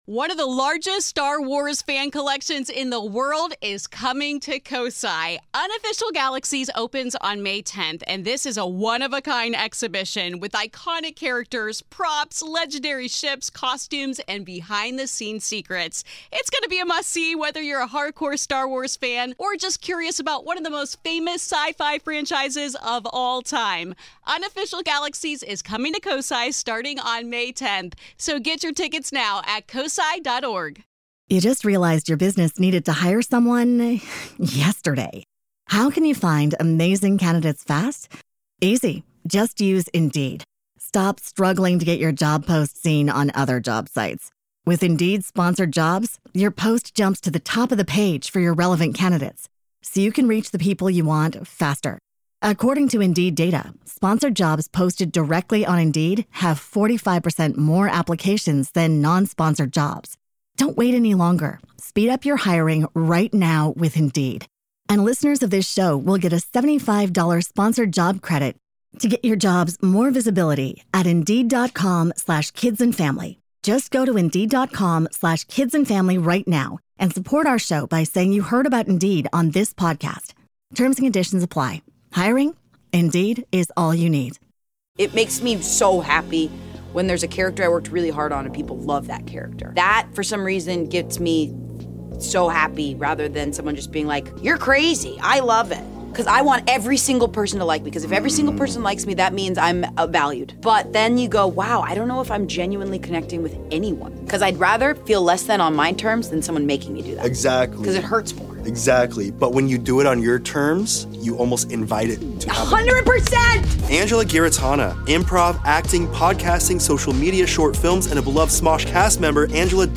a real conversation about slowing down and living in the moment.